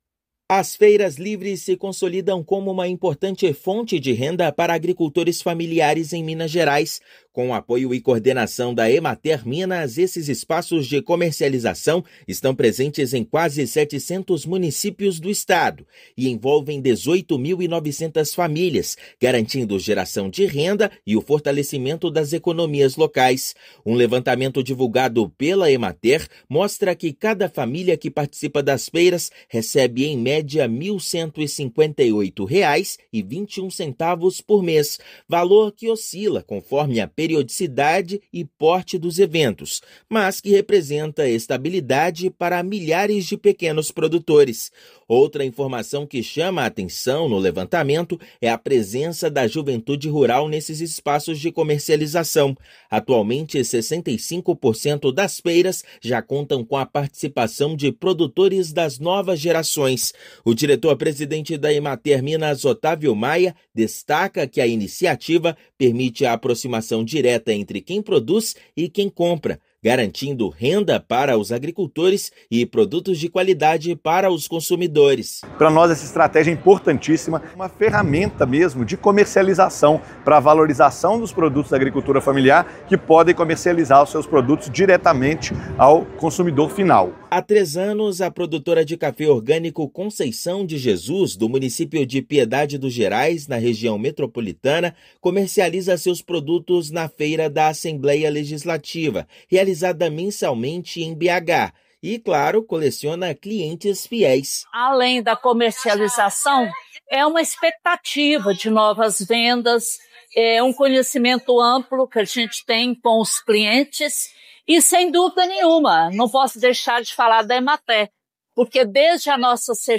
Ação da Emater-MG, em parceria com prefeituras, beneficia 19 mil famílias no campo. Ouça matéria de rádio.